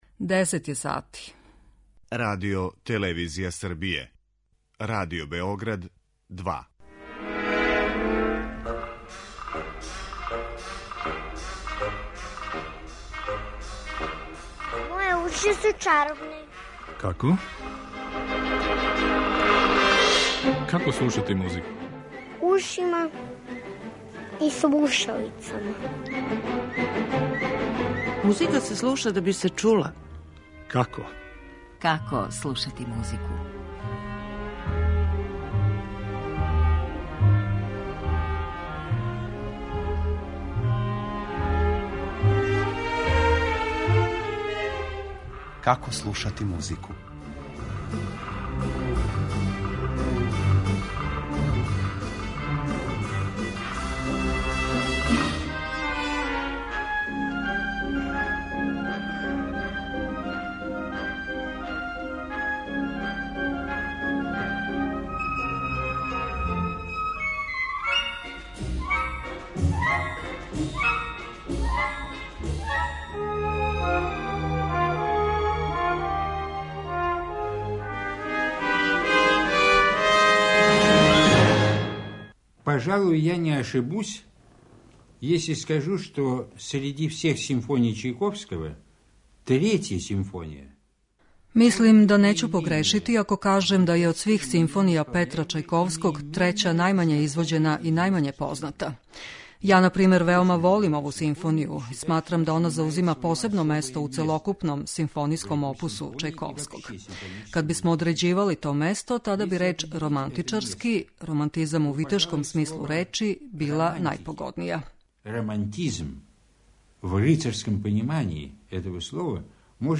Трећа симфонија, која носи надимак пољска због финалног става у ритму полонезе, у сваком случају се издваја од осталих због тога што има пет ставова и једина је у дурском тоналитету.